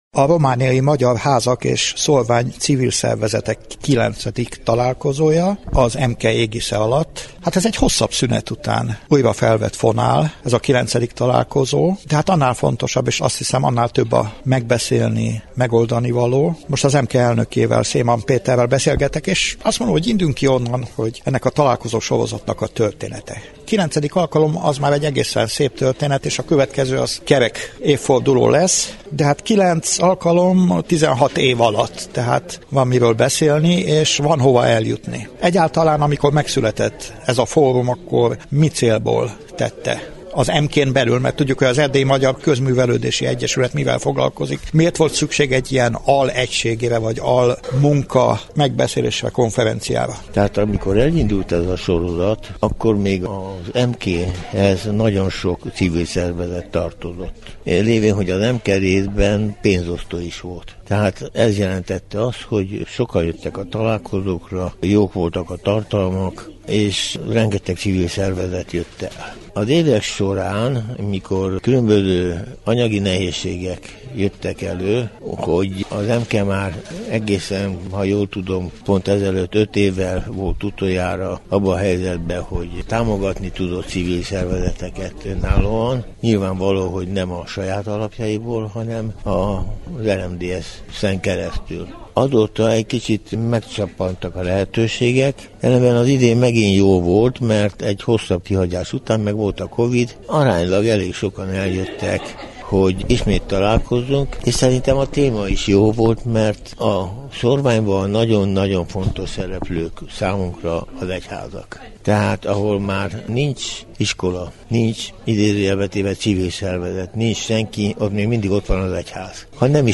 A Romániai Magyar Házak és Szórvány Civil Szervezetek IX. Találkozóján jártunk.
Összeállításunk során a velük készült interjúk is elhangzanak.